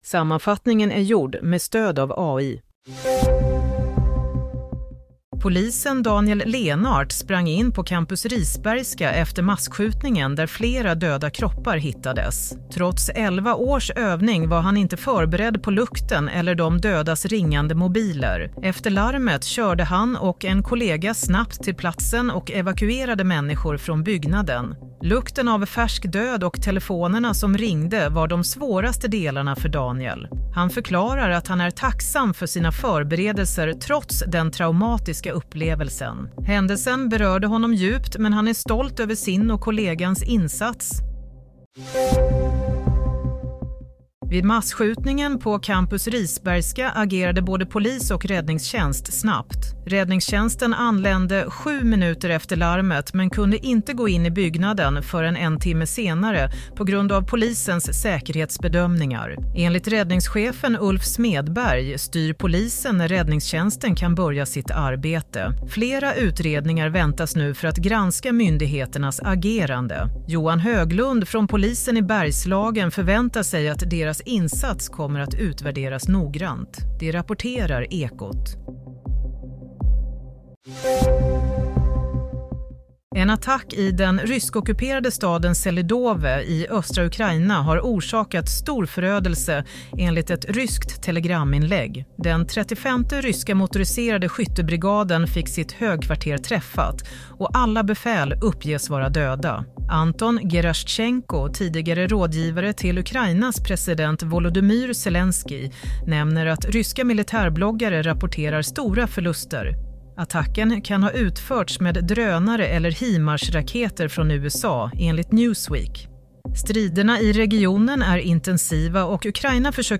Nyhetssammanfattning - 9 februari 07:30
Sammanfattningen av följande nyheter är gjord med stöd av AI.